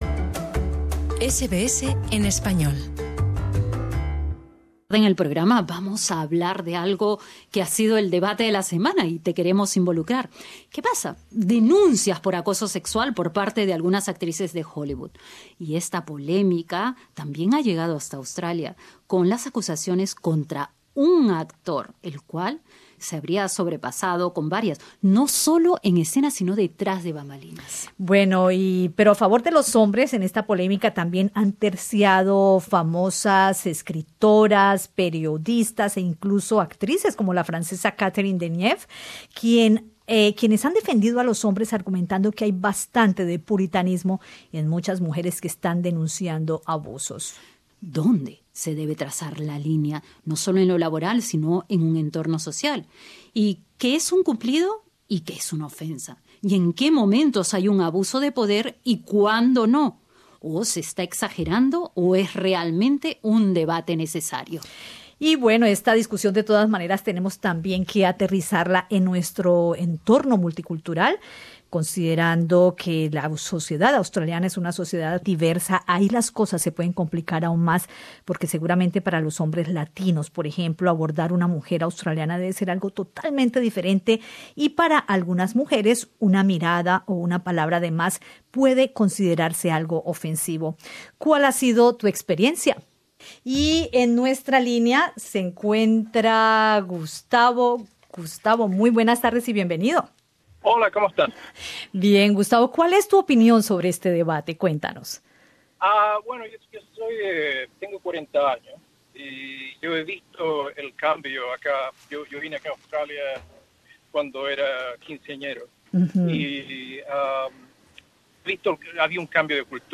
En el contexto del intenso debate originado por denuncias de acoso sexual en el mundo del espectáculo en distintas partes del mundo, incluyendo Australia, oyentes de Radio SBS opinaron al respecto. Los comentarios variaron entre las dificultades que algunos hombres latinos pueden encontrar al tratar de relacionarse con mujeres australianas, si el acoso también se produce desde las mujeres hacia los hombres, hasta el rechazo total a un piropo.